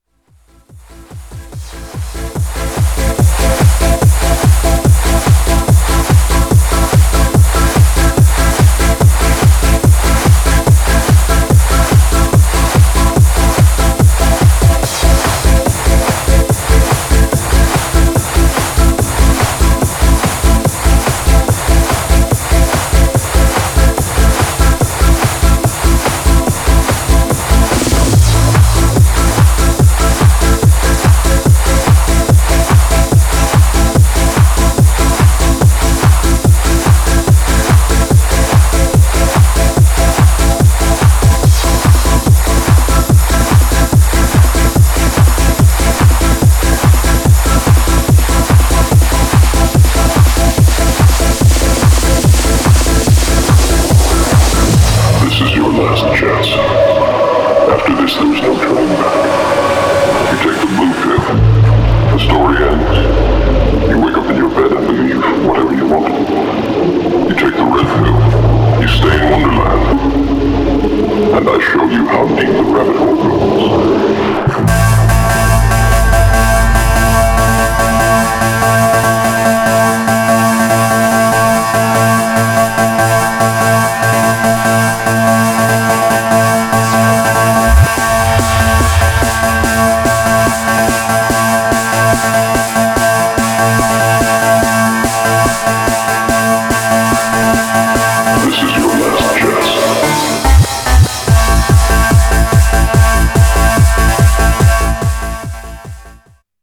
Styl: Techno, Trance